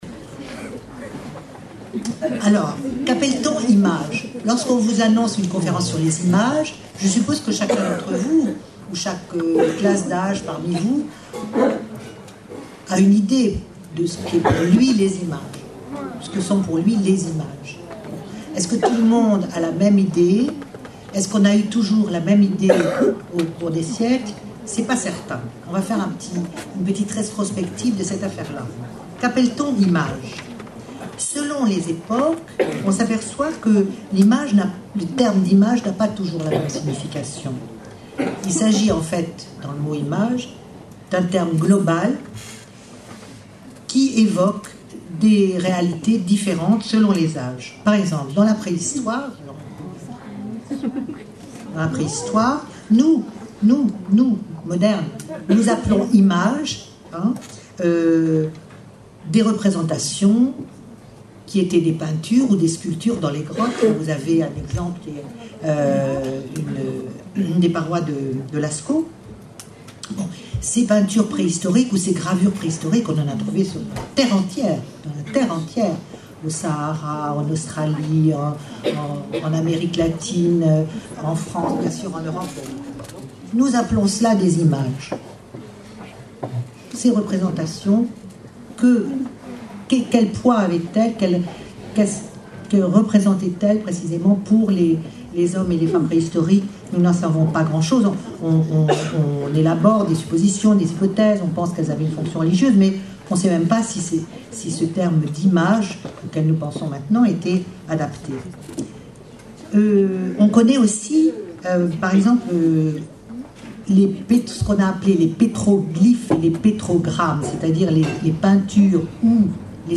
Une conférence de l'UTLS au Lycée
Lycée agricole et rural de Soule ( Mauléon 64)